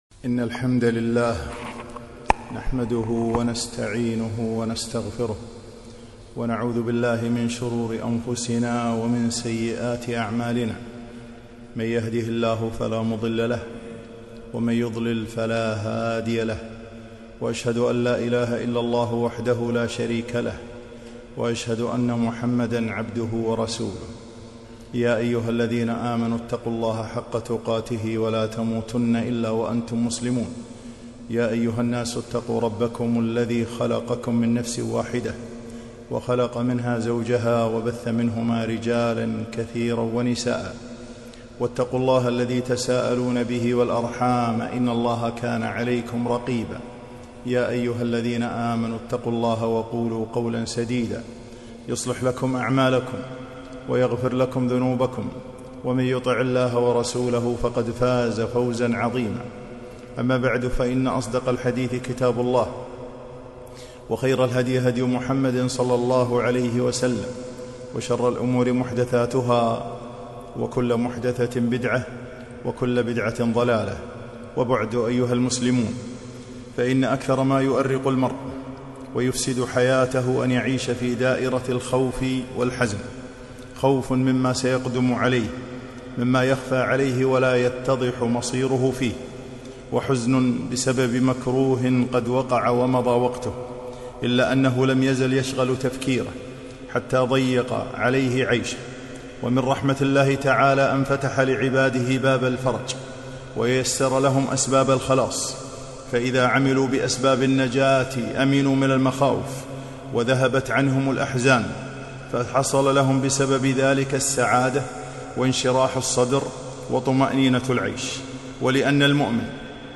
خطبة - لاخوف عليهم ولا هم يحزنون